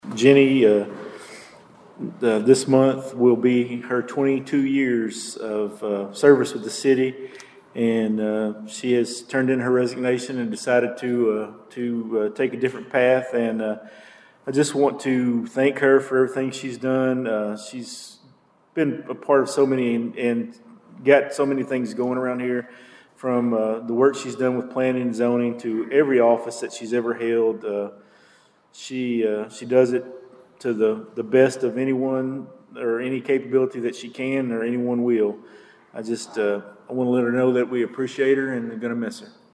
During Monday night’s meeting, the Princeton City Council moved forward with plans for sidewalk repairs, received updates on the winter storm’s impact, and learned that a longtime city employee is stepping down.